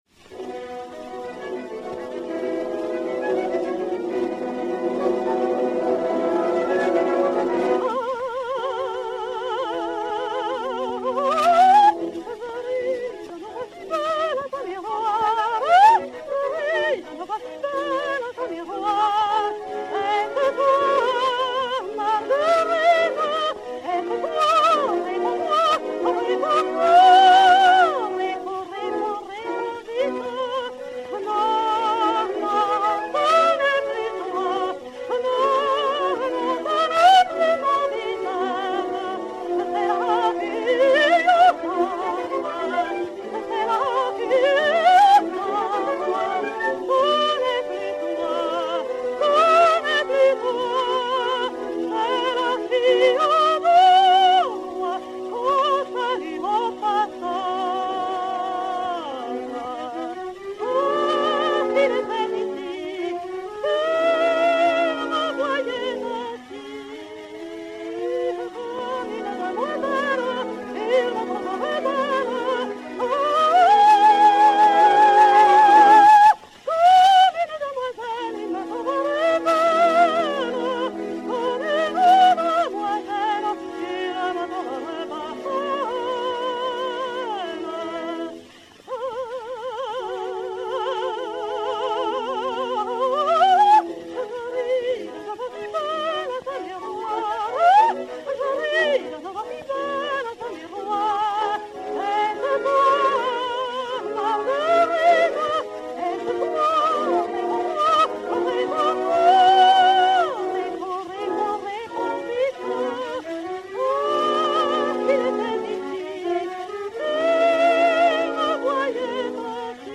soprano suédois
Sigrid Arnoldson (Marguerite) et Orchestre
Disque Pour Gramophone 33613, mat. 1295r, enr. à Berlin en juin 1906